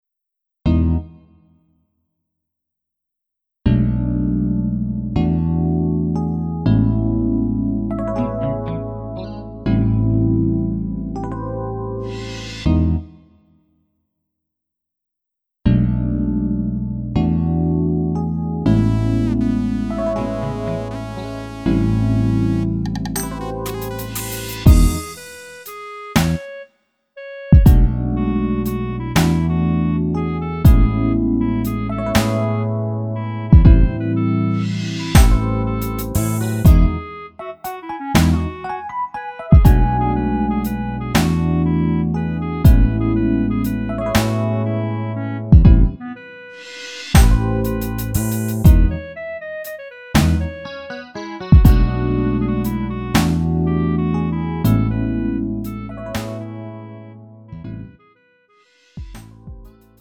음정 -1키 3:41
장르 구분 Lite MR